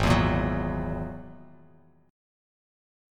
Ab7sus2#5 chord